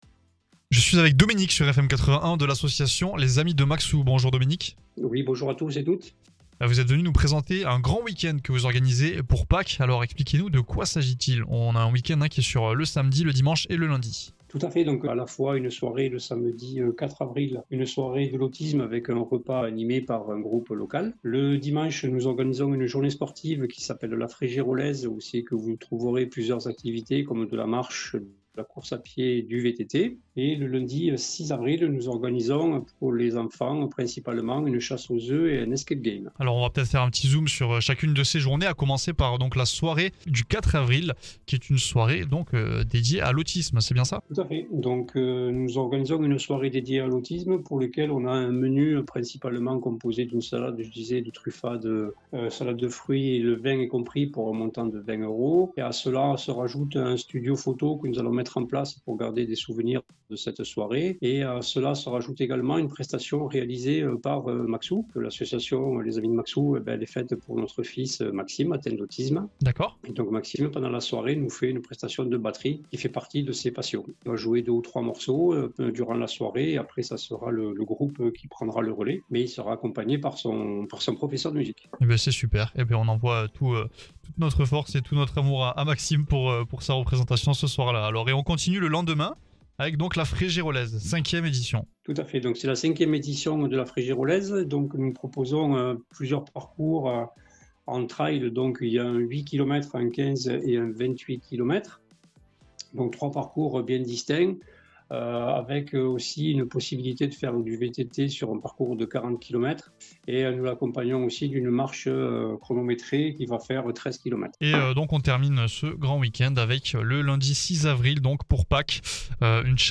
FM81, radio proposant de l’info loisir du Tarn et tous les standards français des années 60, 70 et 80